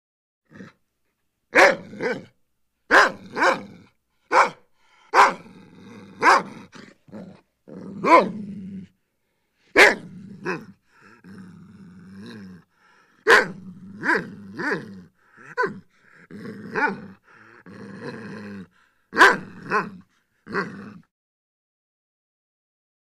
Dog, German Shepherd Snarls And Growls With One High Pitched Yelp. Medium Perspective.